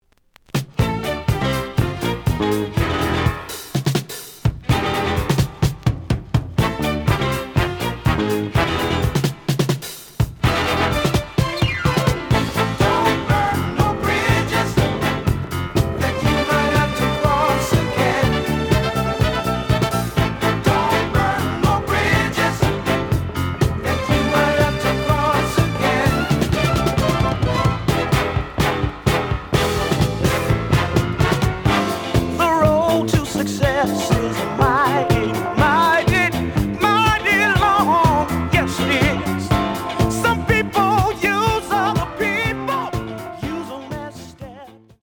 ●Genre: Disco
Slight edge warp.